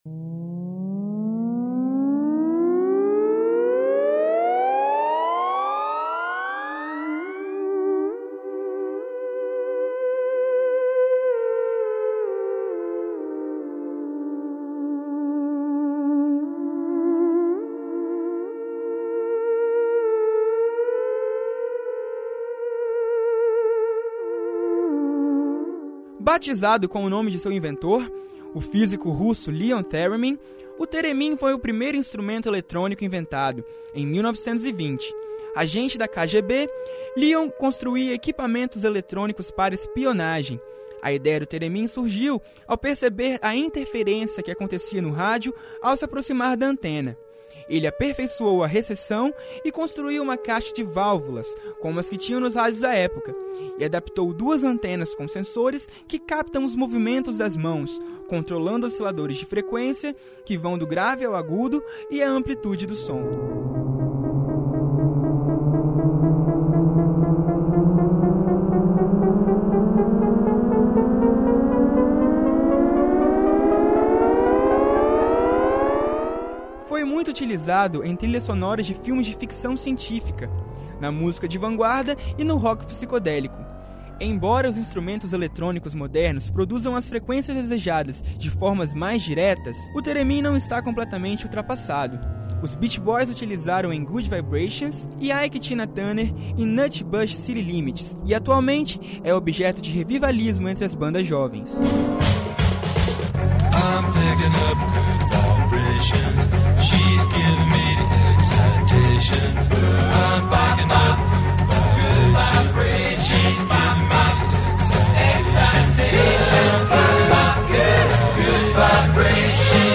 A thereminsta inglesa